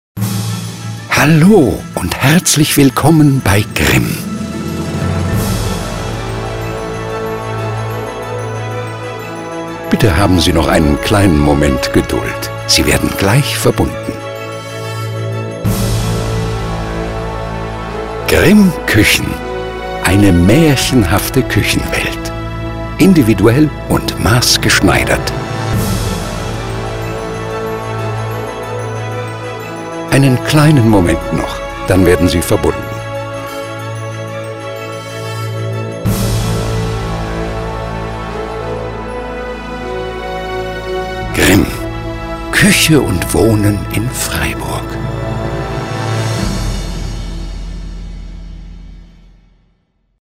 Die dunkle, rauchig-warme Stimme war das große Kapital von Wolfgang Hess und kam auch in unzähligen Dokumentationen und Fernsehreportagen als Erzählstimme zum Einsatz.
H Ö R B E I S P I E L E – in der finalen Tonmischung:
Kreative Warteschleife Ansage
Grimm-Warteschleife.mp3